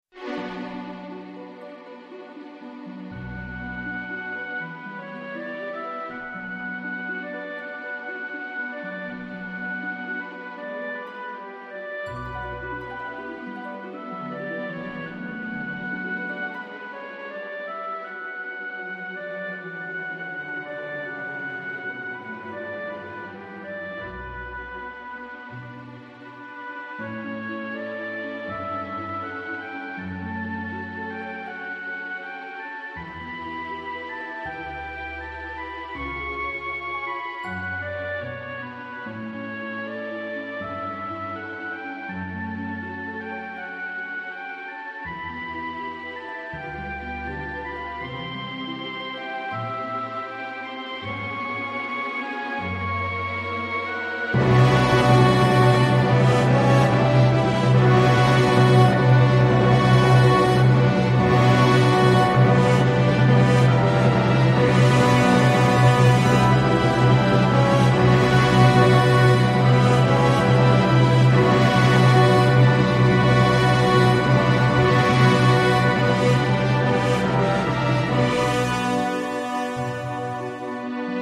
クラシック音楽の曲名